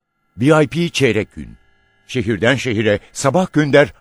Estimate clearly shows that the original speech is obtained.